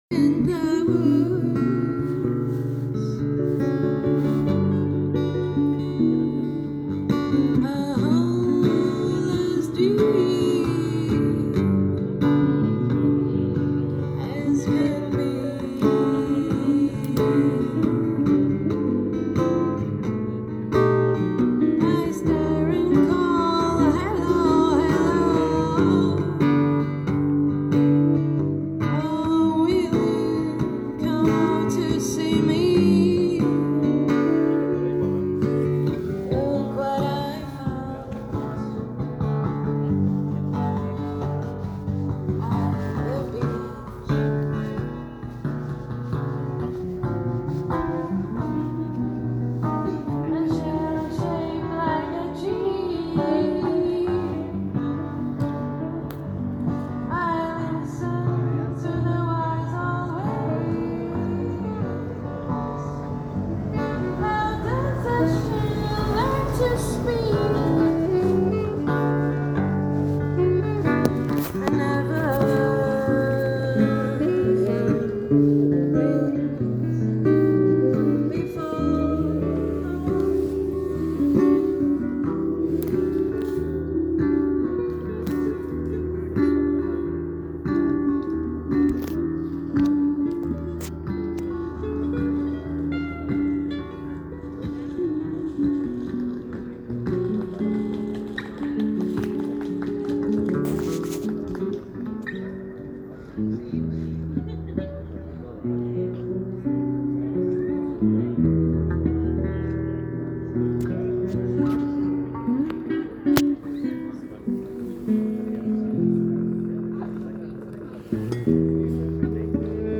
Am Vorabend meiner Abreise spielte eine Band diese atmosphärischen
und leicht melancholischen Klänge.